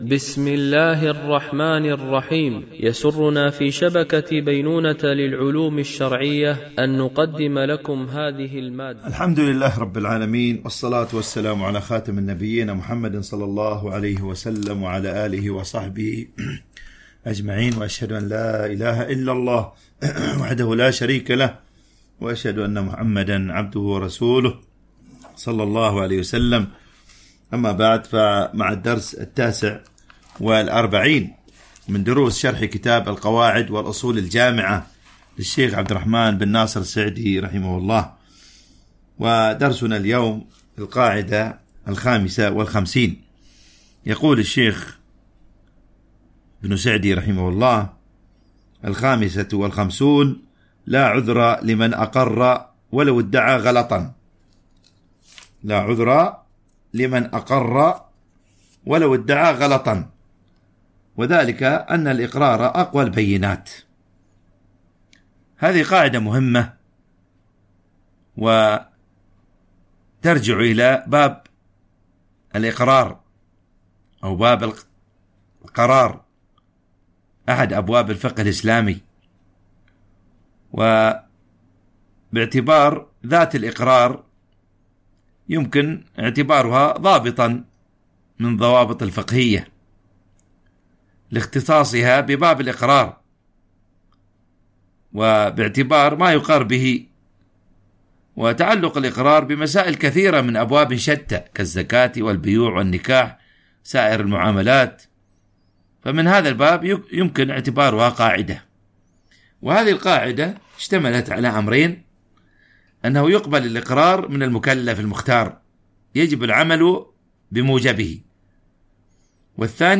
شرح القواعد والأصول الجامعة والفروق والتقاسيم البديعة النافعة - الدرس 49 ( لا عذر لمن أقر ولو ادعى غلطا )